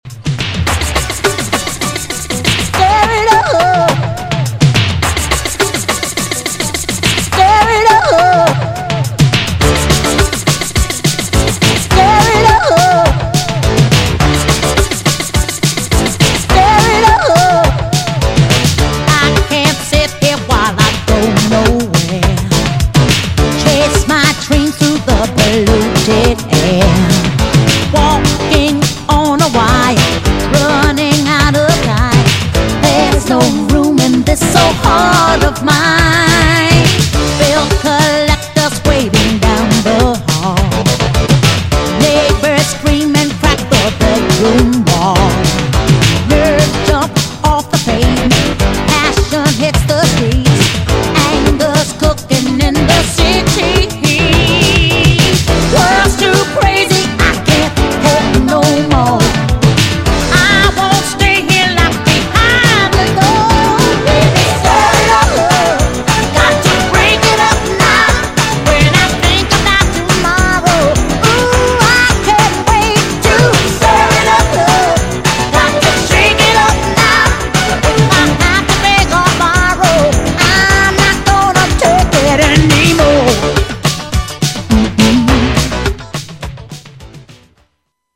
GENRE Dance Classic
BPM 91〜95BPM
# アーバン # デュエット # ブラコン # ミディアム # メロウ